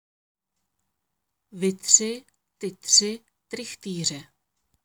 Tady si můžete stáhnout audio na výslovnost Ř: Vytři ty tři trychtýře.
vytri_ty_tri_trychtyre.m4a